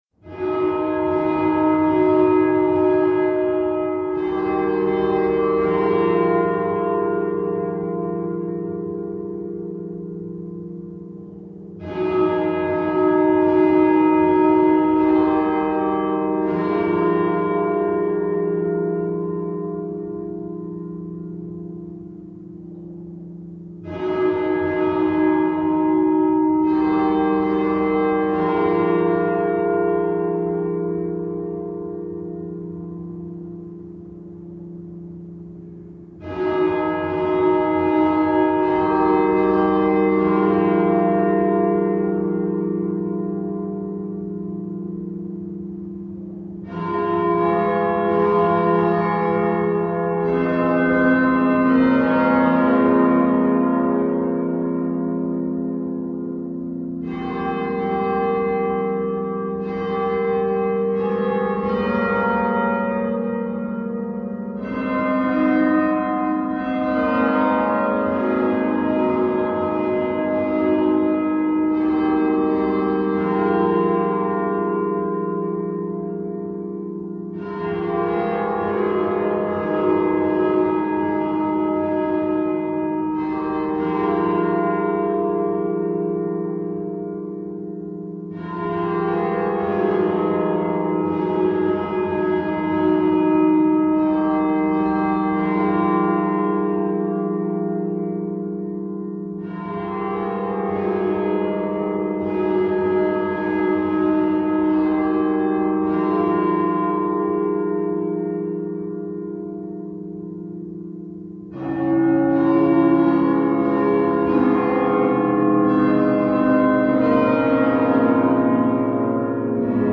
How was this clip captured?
A site-specific sound art performance.